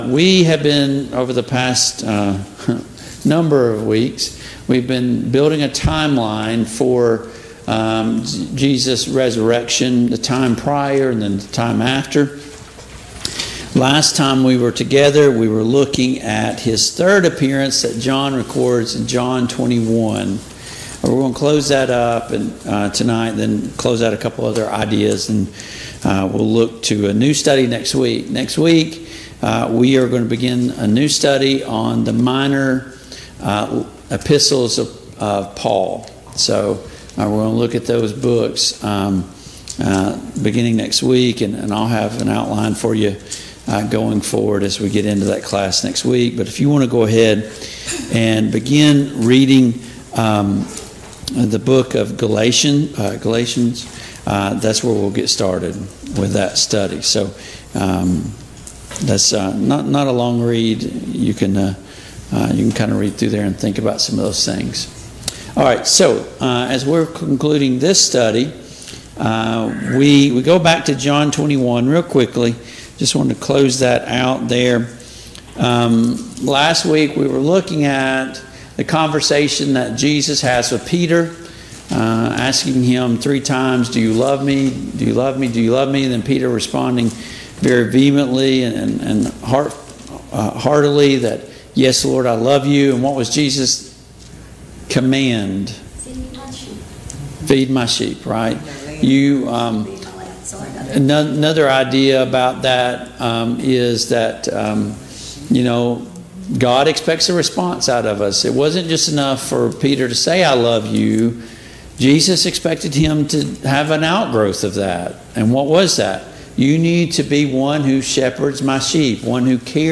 Service Type: Mid-Week Bible Study